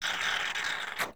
plunger.wav